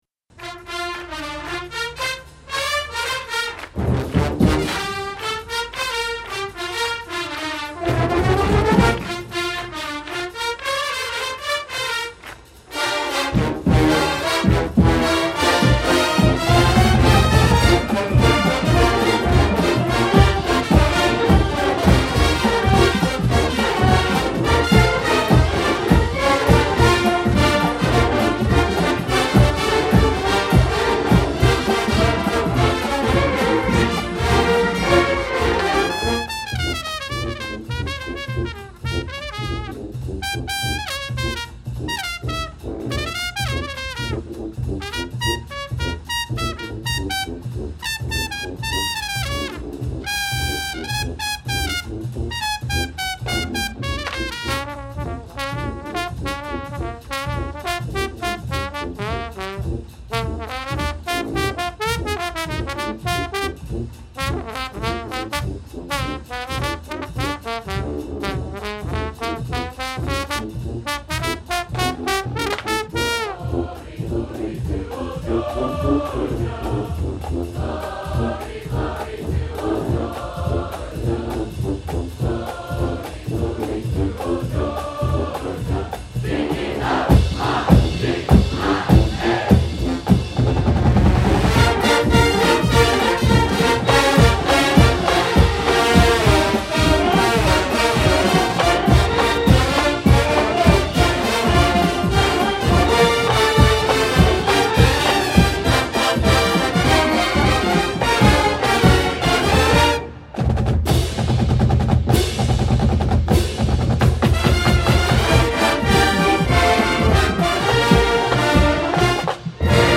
Songs performed by the Redcoat Marching Band
Dixieland Version